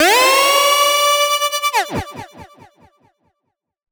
SOUTHSIDE_fx_tremosiren.wav